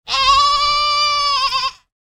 دانلود آهنگ بز 1 از افکت صوتی انسان و موجودات زنده
جلوه های صوتی
دانلود صدای بز 1 از ساعد نیوز با لینک مستقیم و کیفیت بالا